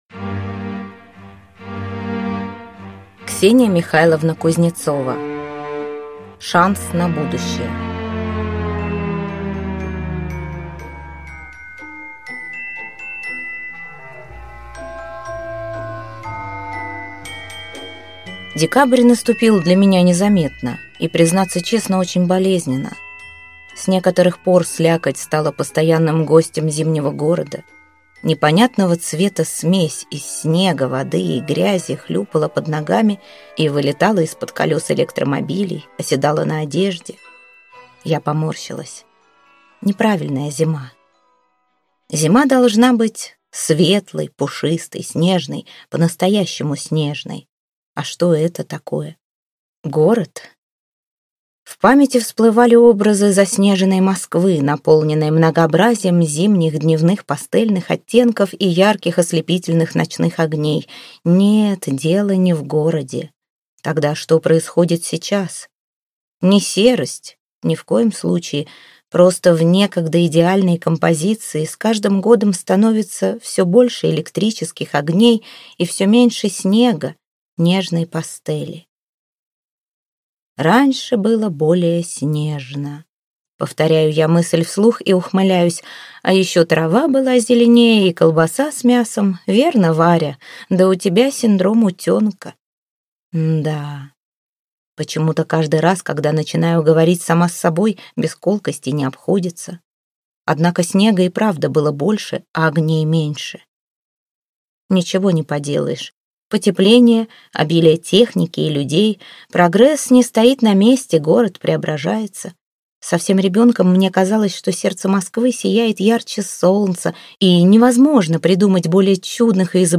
Аудиокнига Шанс на будущее | Библиотека аудиокниг